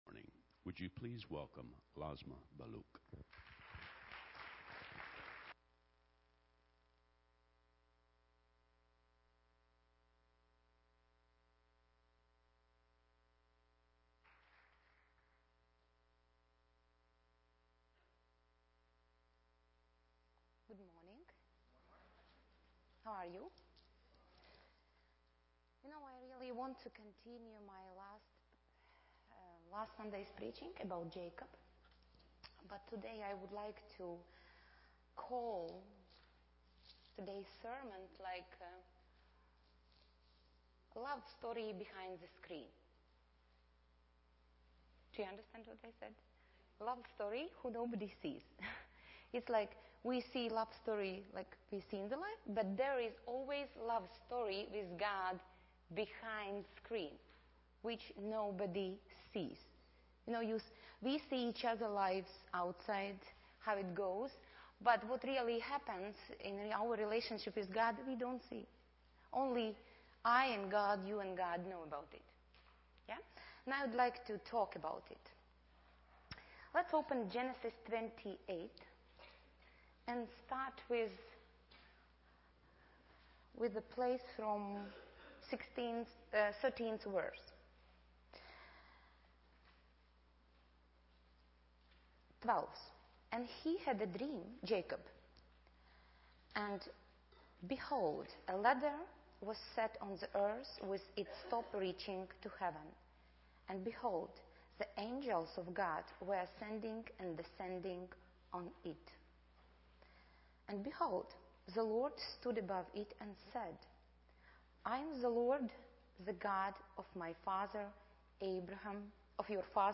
teaches on making our faith our own, giving examples from Jacob's experience in Genesis 28-31, where the Lord taught Jacob to know Himself as his own God, not just his father's and grandfather's God.